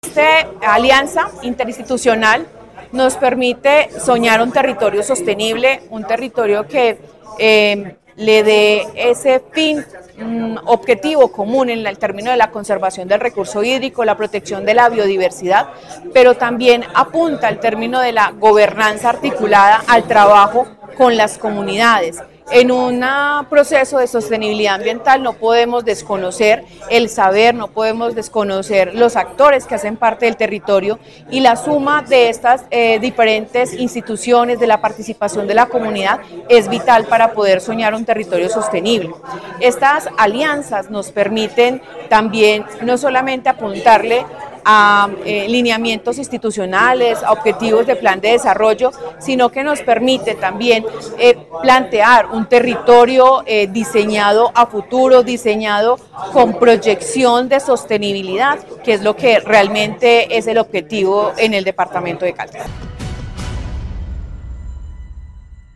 Paola Andrea Loaiza Cruz, secretaria de Medio Ambiente de Caldas